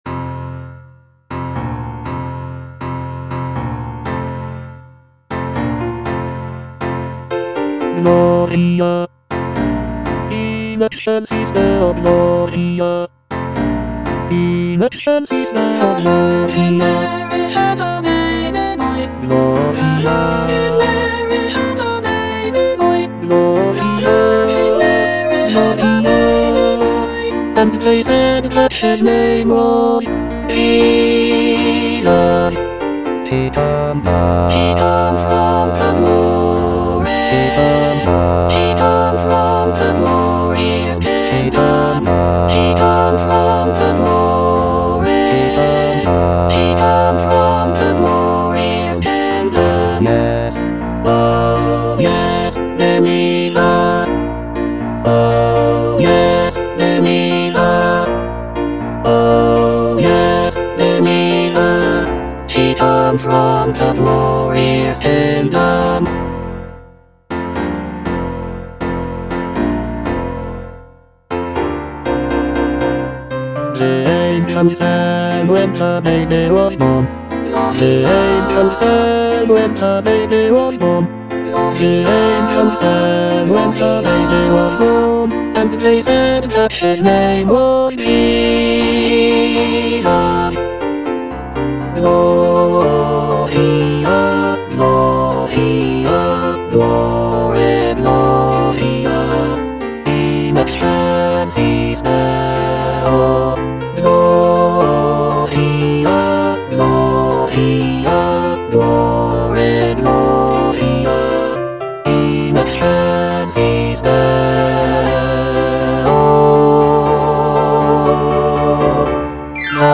Bass (Emphasised voice and other voices)
" set to a lively calypso rhythm.
incorporating Caribbean influences and infectious rhythms.